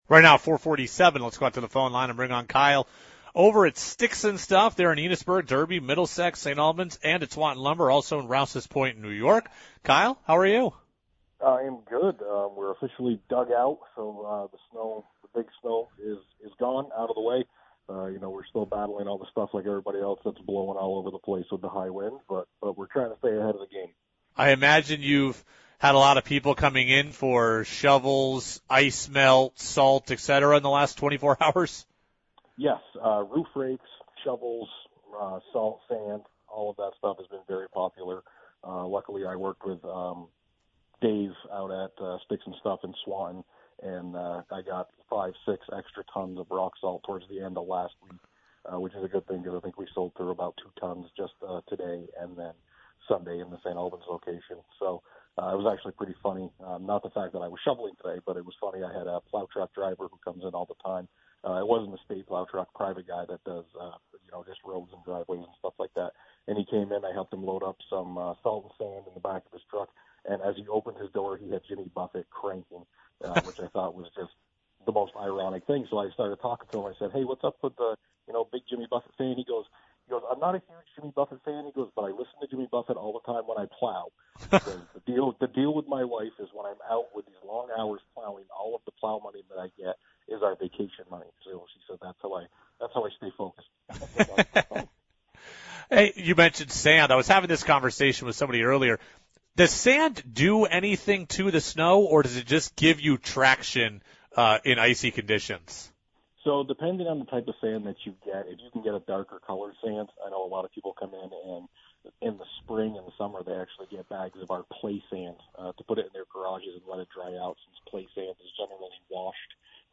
live on WDEV and Radio Vermont Group.